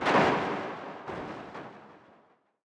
fx_crawlerexplosion_dish.wav